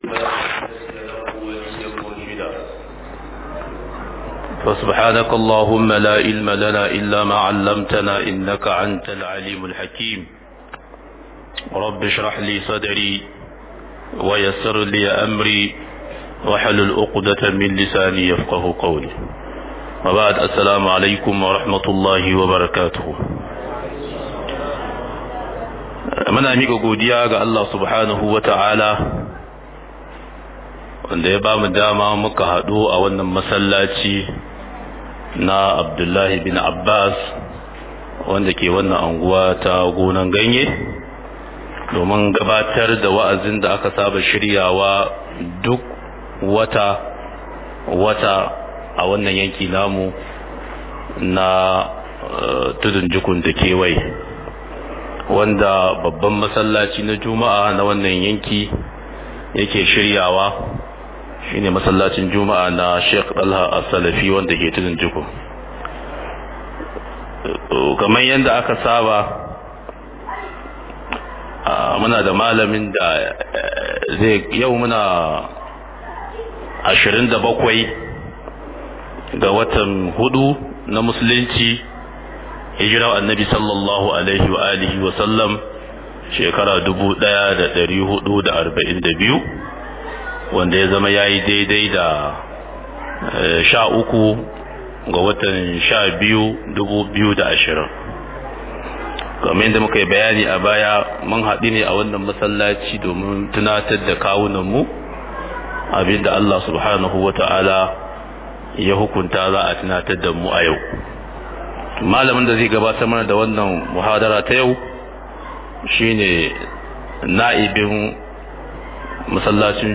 Mhdr Imni da tsaro_ Merged files_0 - Muhadarori Da Lakcoci